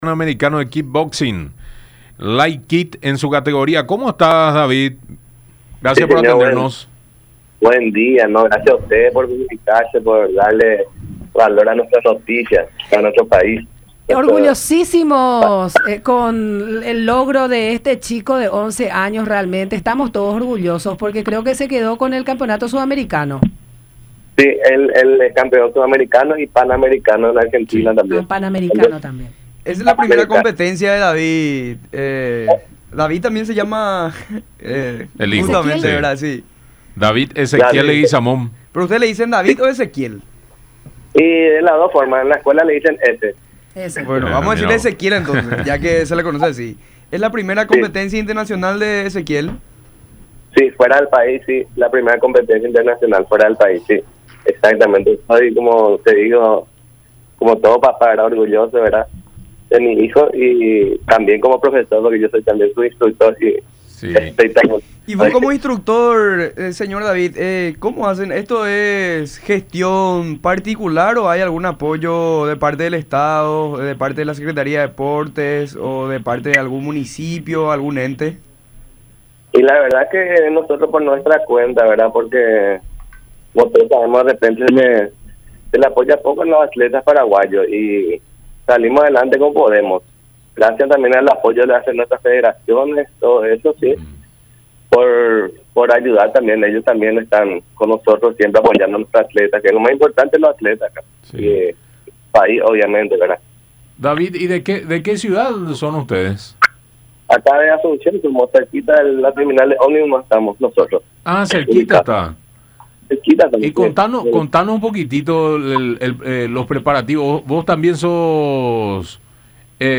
en conversación con La Mañana De Unión a través de Unión TV y radio La Unión